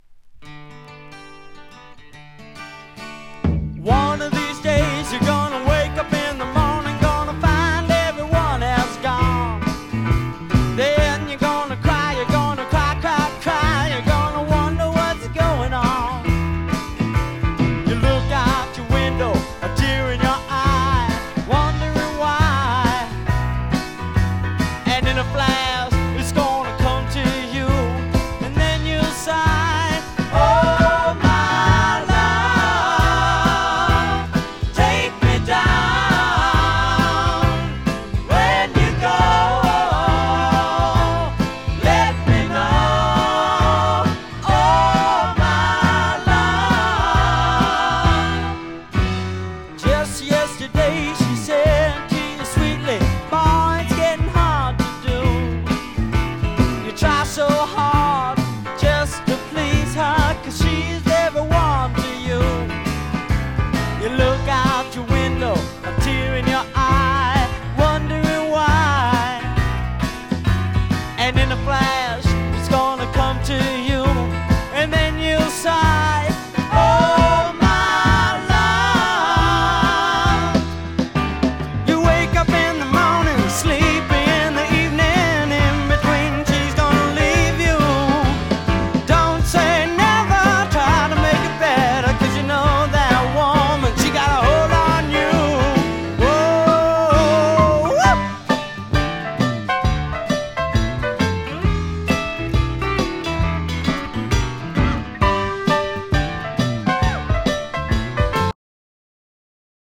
ピアノ系SSWの唯一のアルバム。